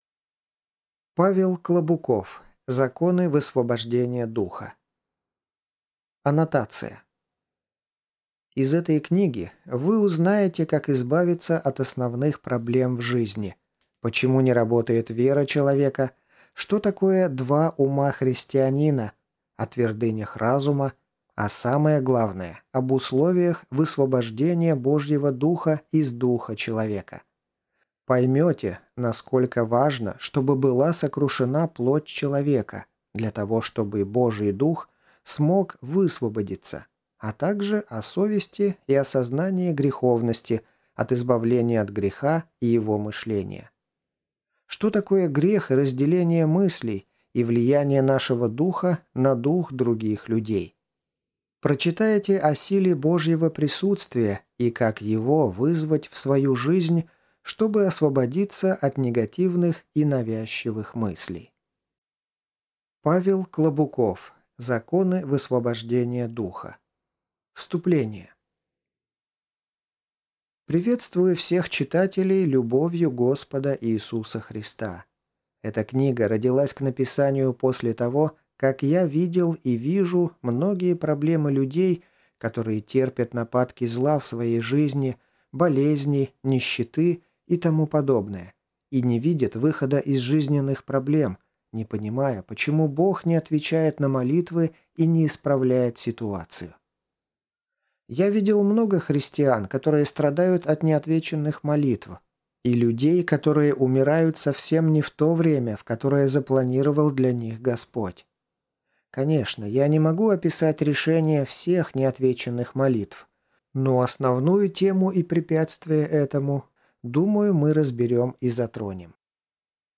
Аудиокнига Законы высвобождения Духа | Библиотека аудиокниг